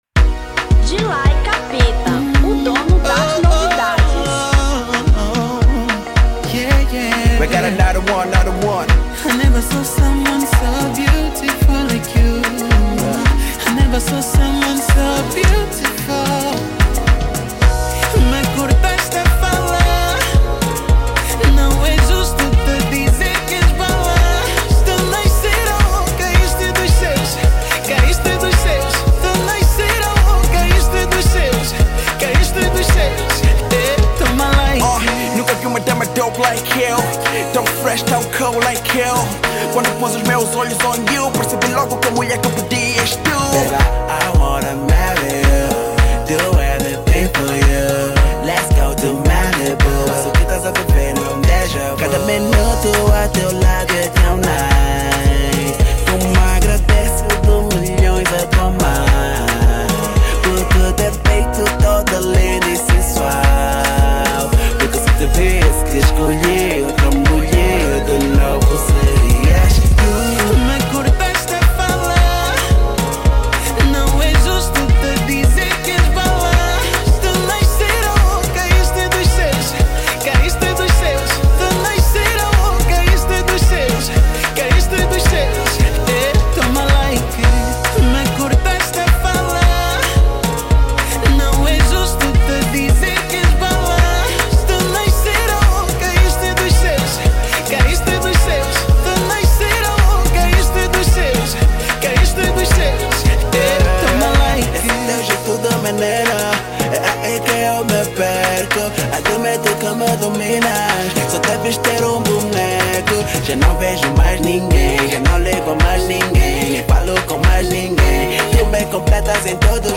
Zouk 2016